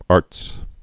(ärts)